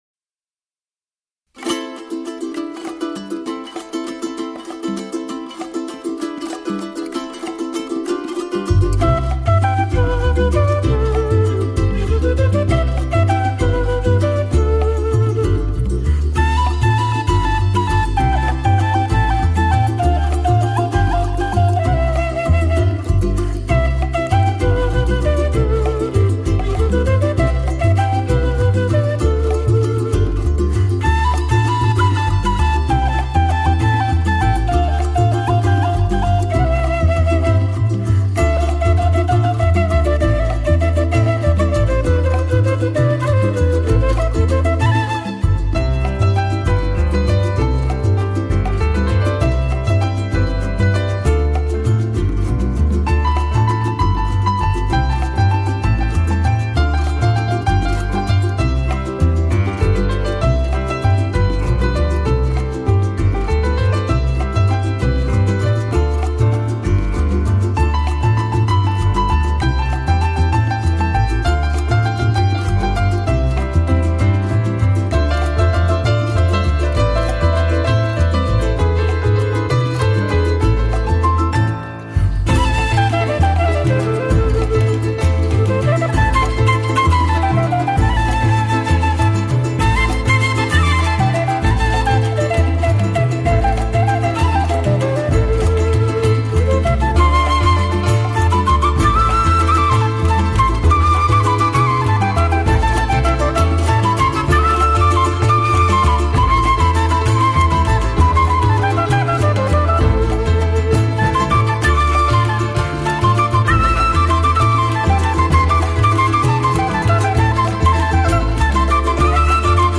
（浓厚南美气息，排笛旋律轻快悠扬）